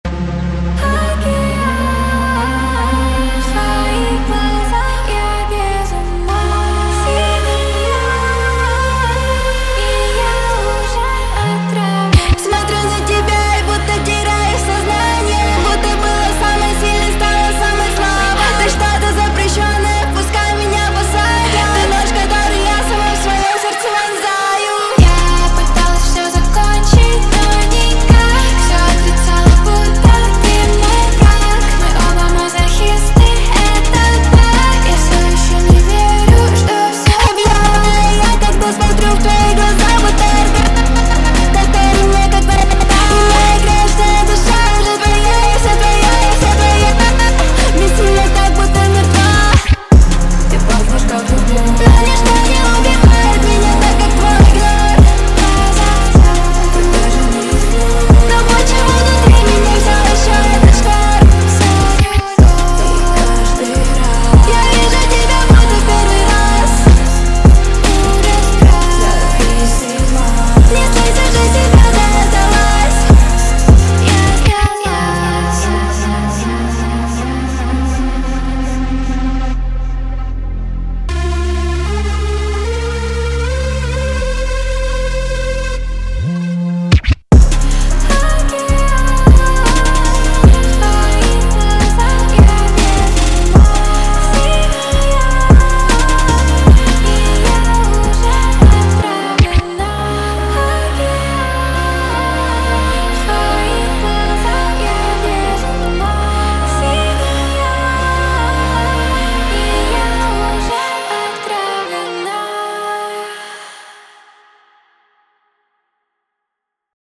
Рэп, Новинки музыки в пятницу